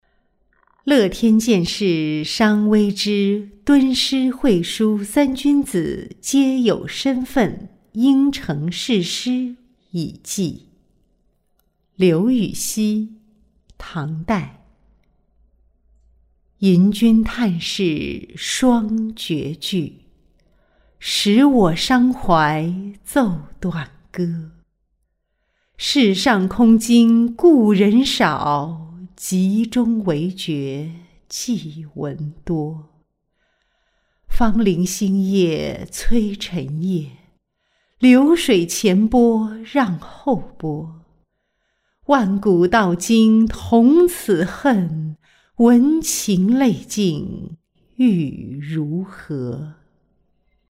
乐天见示伤微之敦诗晦叔三君子皆有深分因成是诗以寄-音频朗读